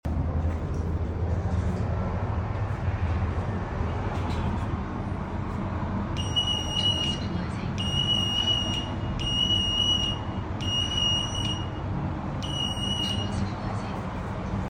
Testing sound levels in a sound effects free download
Testing sound levels in a Schindler elevator lift